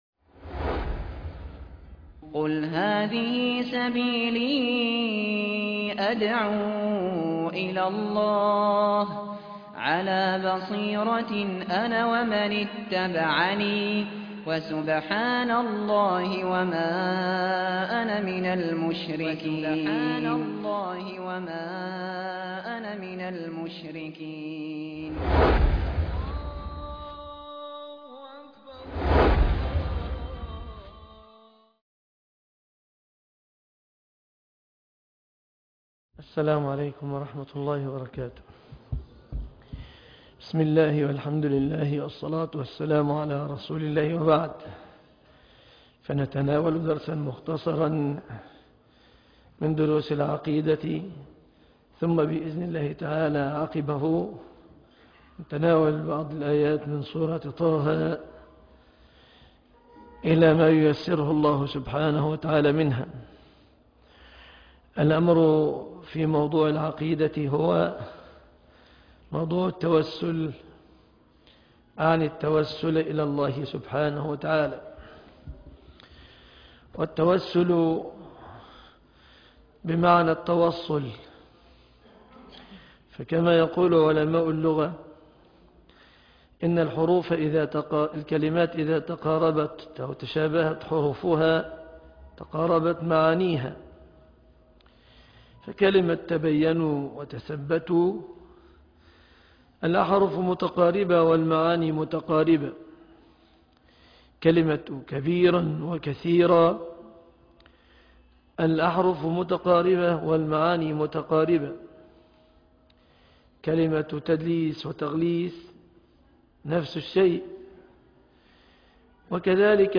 التوسل إلى الله (18/2/2014) دروس العقيدة - مجمع التوحيد بالمنصورة - فضيلة الشيخ مصطفى العدوي